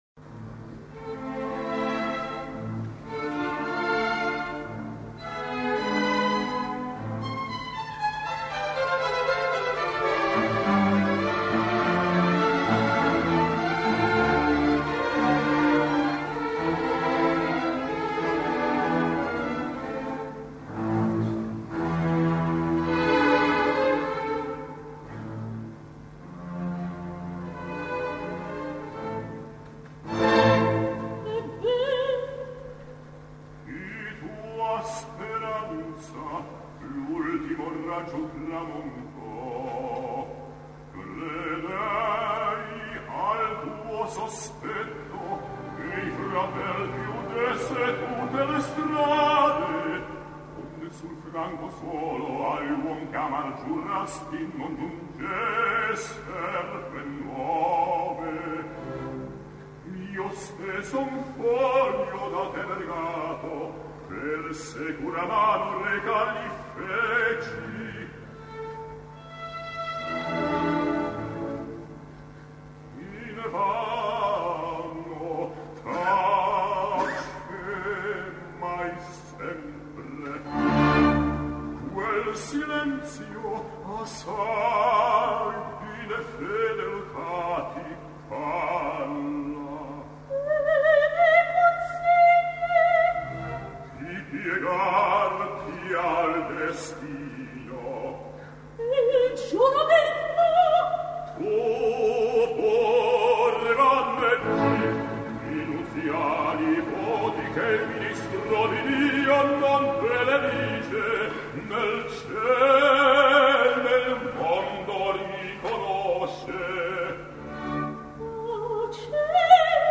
Záznamy z představení / opera records